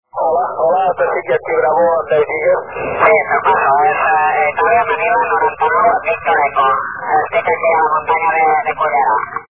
Archivos sonido de QSOs en 10 GHz SSB
398 Kms Tropo Mar